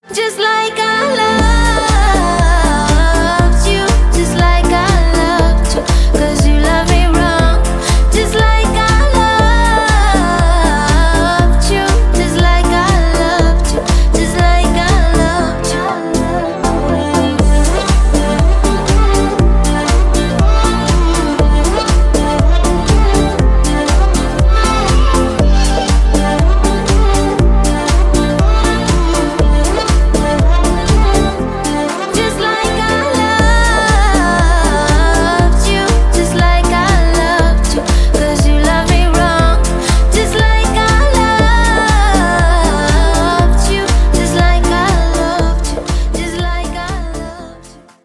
• Качество: 320, Stereo
deep house
dance
vocal